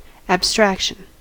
abstraction: Wikimedia Commons US English Pronunciations
En-us-abstraction.WAV